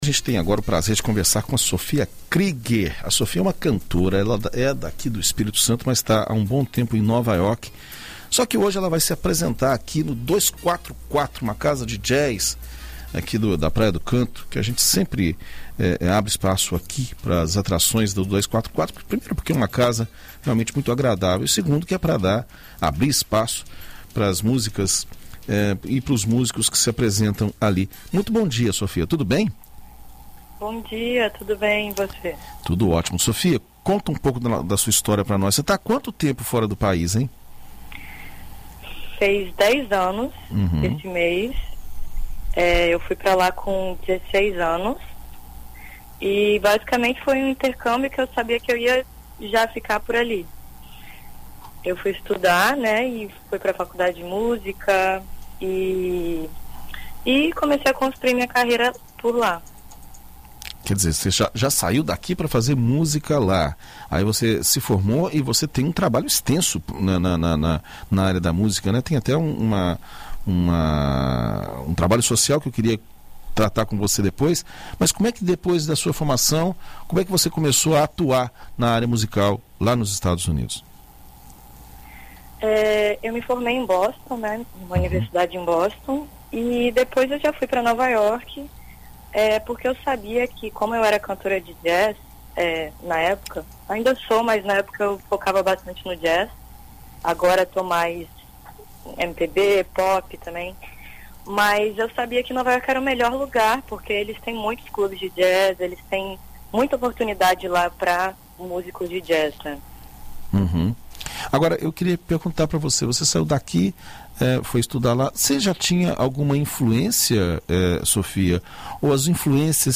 Em entrevista à BandNews FM Espírito Santo nesta quinta-feira (20)